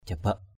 /ʥa-baʔ/ (đg.) châm, chích = piquer. to prick. jalakaow jabak jl_k<| jbK con ong chích = l’abeille pique. the bee stings.